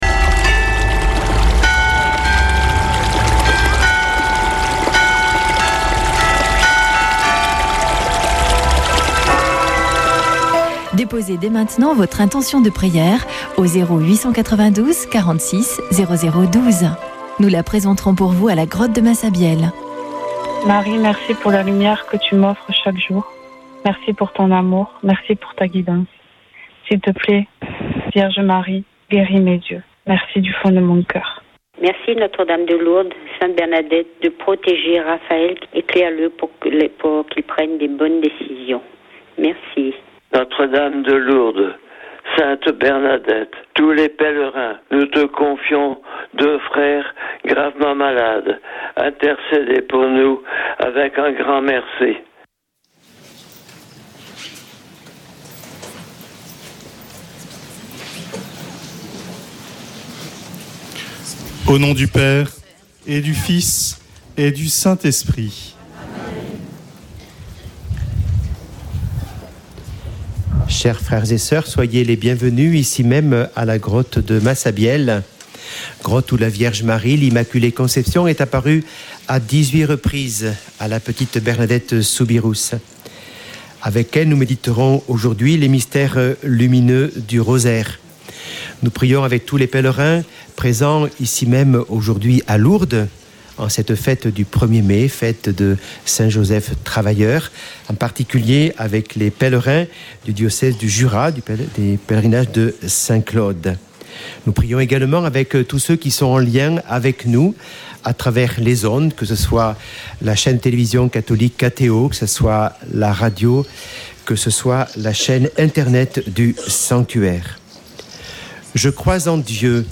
Prière et Célébration
Une émission présentée par Chapelains de Lourdes